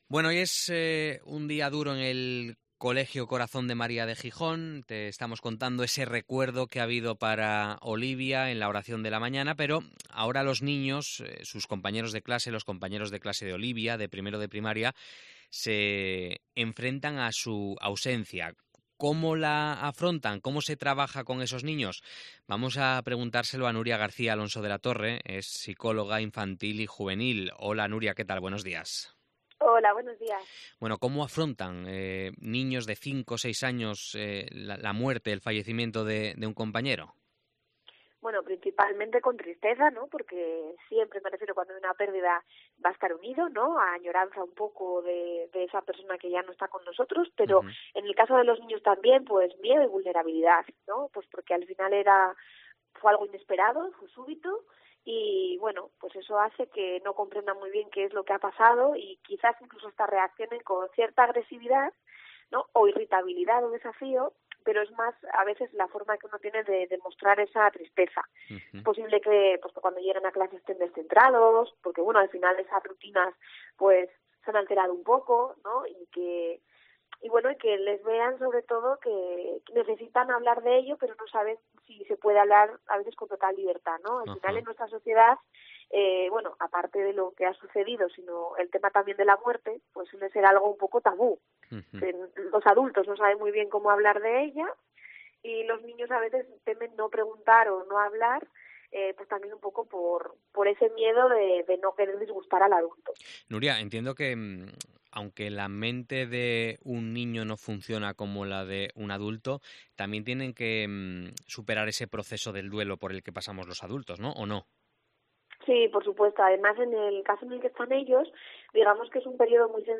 Entrevista
psicóloga infantil